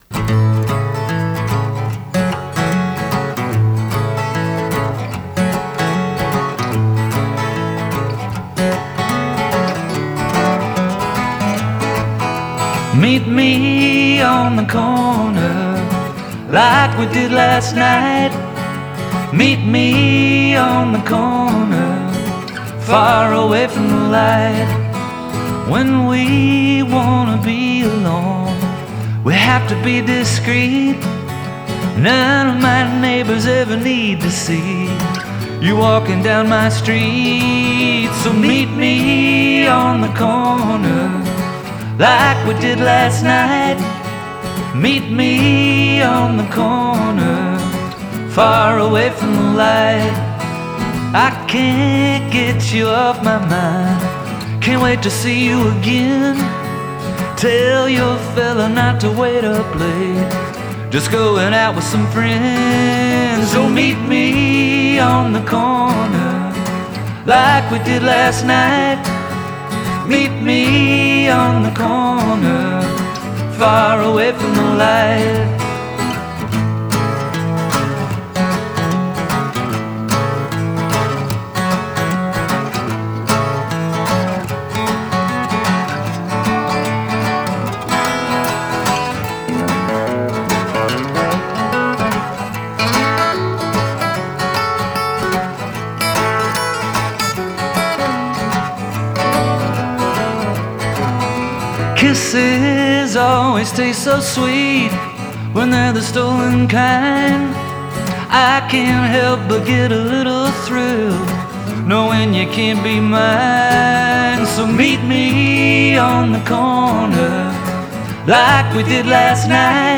jaunty guitar work and hooks